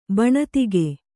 ♪ baṇatige